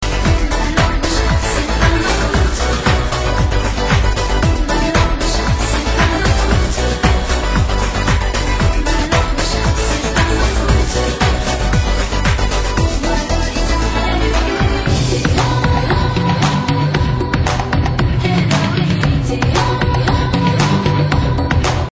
Arrow trance song female vocals, possibly french